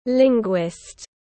Nhà ngôn ngữ học tiếng anh gọi là linguist, phiên âm tiếng anh đọc là /ˈlɪŋɡwɪst/.
Linguist /ˈlɪŋɡwɪst/